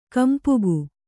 ♪ kampugu